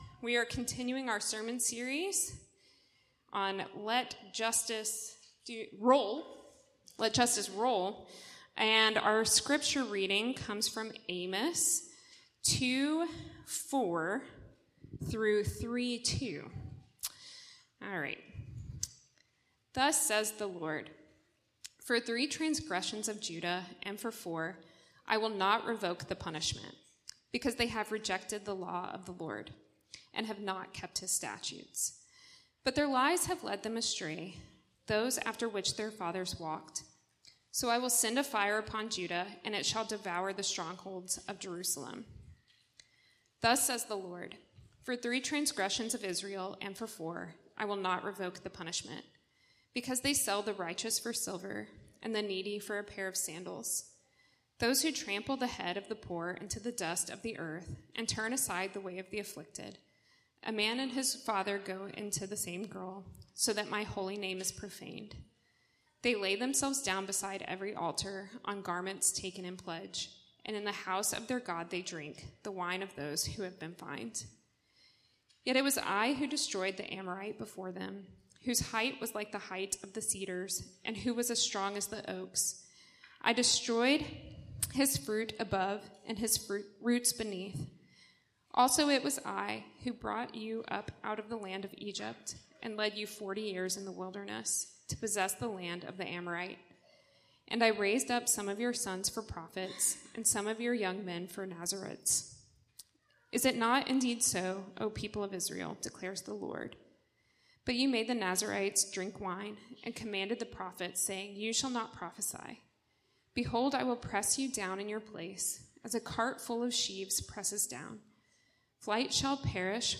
Weekly sermons from Redeemer City Church in Madison, Wisconsin, which seeks to renew our city through the gospel.